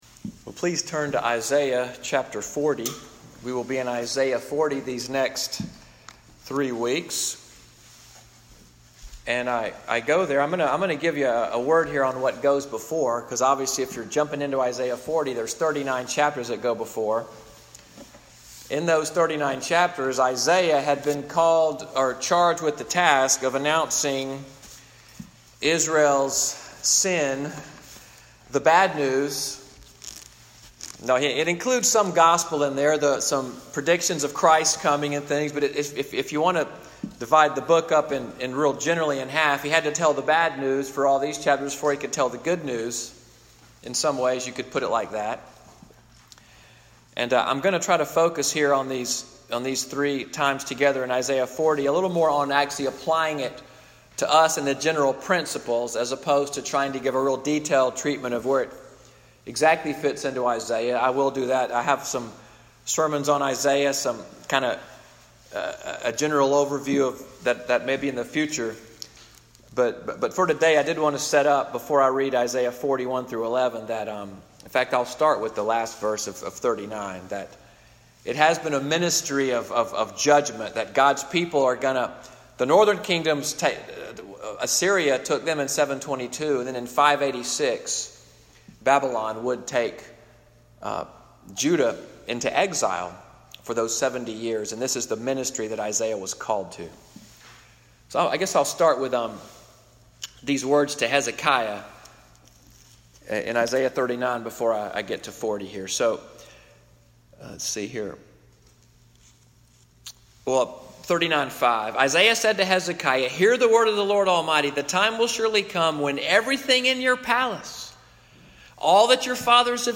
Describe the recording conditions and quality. Morning Worship at Little Sandy Ridge Presbyterian Church, Fort Deposit, AL, “Behold Your God, part 1,” (24:48) August 12, 2018.